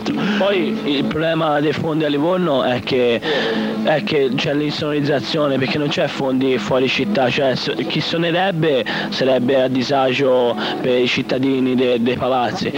MusicaDroga Intervista.